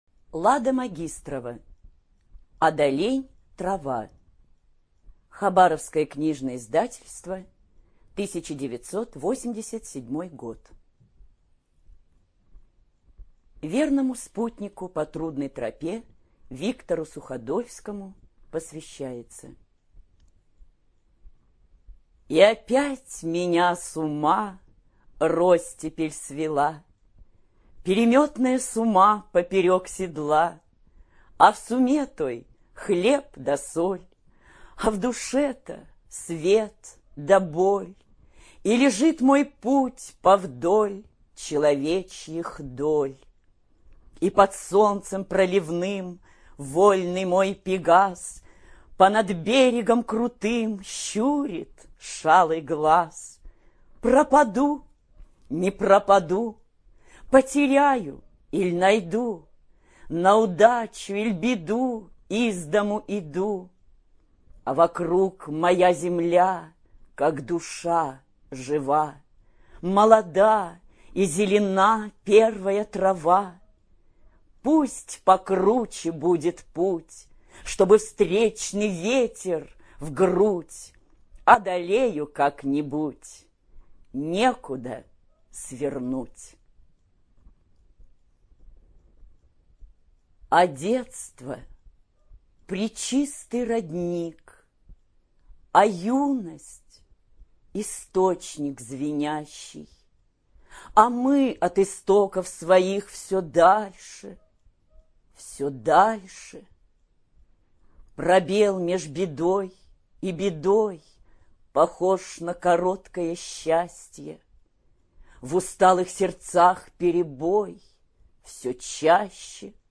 ЖанрПоэзия
Студия звукозаписиХабаровская краевая библиотека для слепых